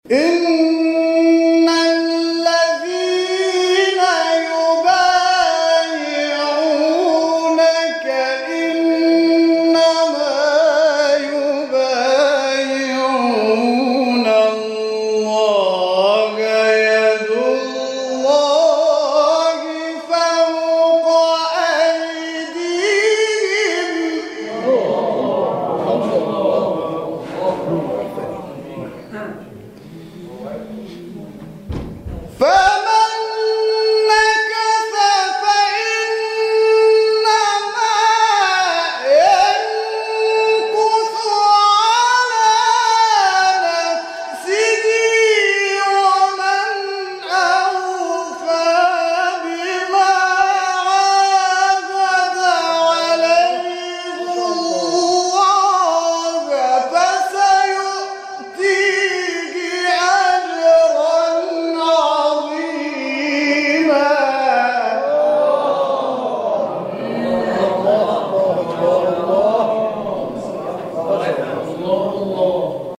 شبکه اجتماعی: فرازهای صوتی از تلاوت قاریان ممتاز کشور را می‌شنوید.
سوره فتح در مقام رست